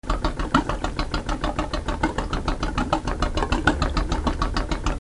Motore a scoppio
Rumore di motore a scoppio 4 tempi al minimo. Biplano, elicottero, moto, auto d'epoca, suono versatile.
GURGLE2.mp3